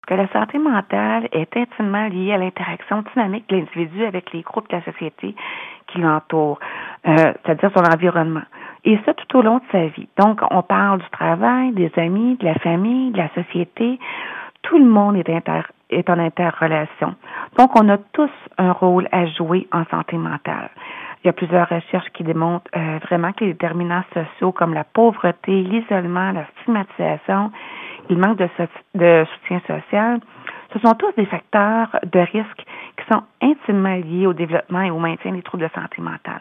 Hablando con ella por téléfono